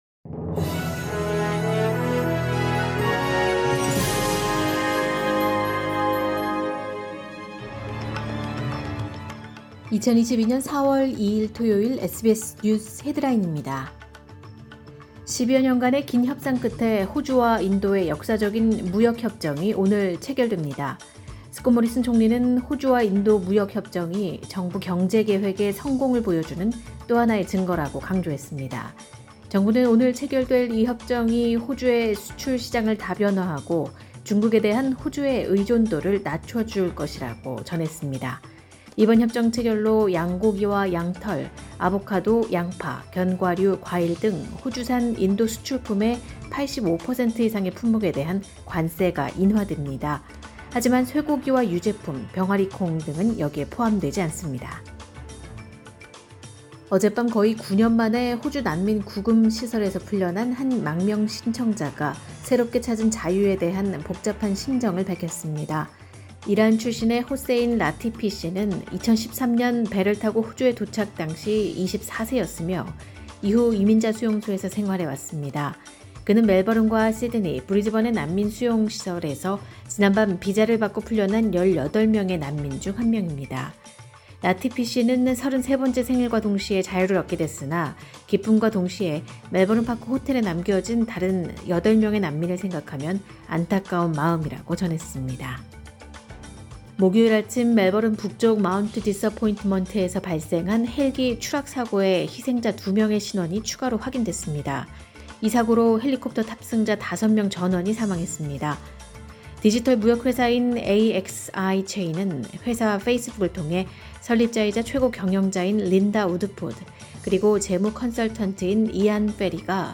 2022년 4월 2일 토요일 SBS 뉴스 헤드라인입니다.